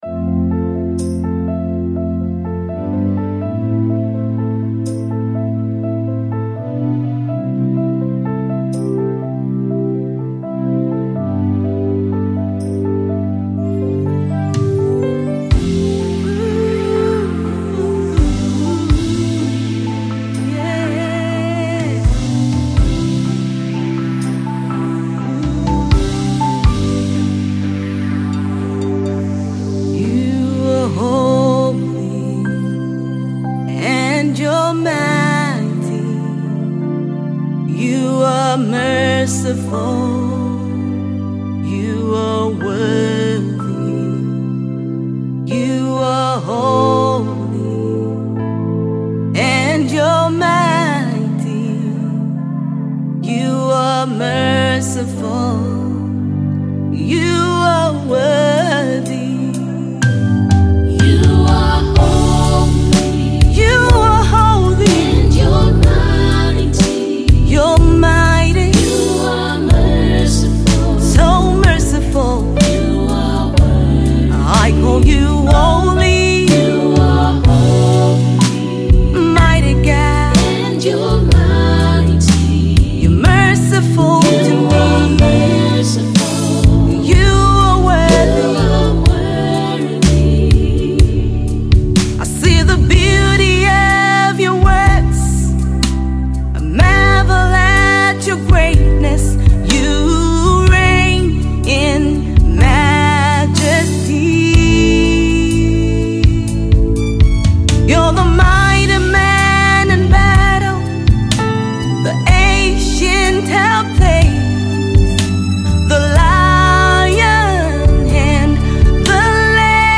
a simple worship song